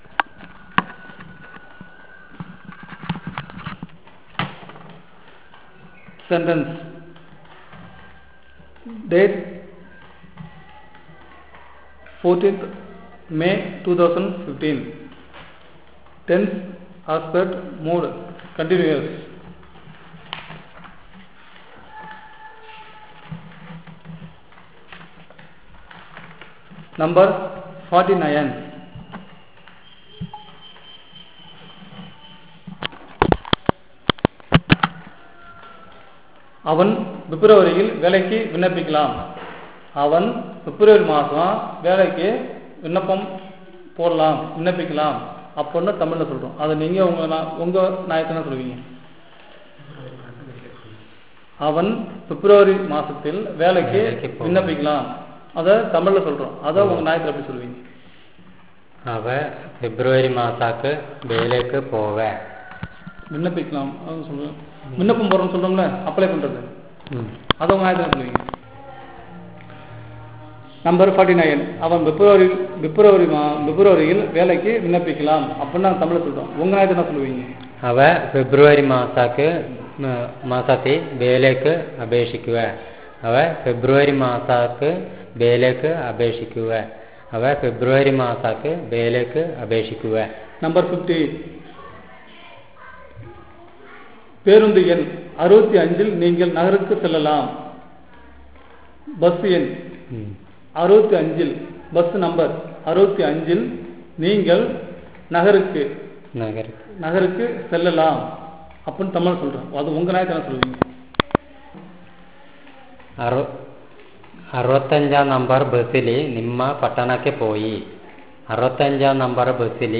NotesThis is an elicitation of sentences with various expressions of tense, aspect and mood, using the SPPEL Language Documentation Handbook.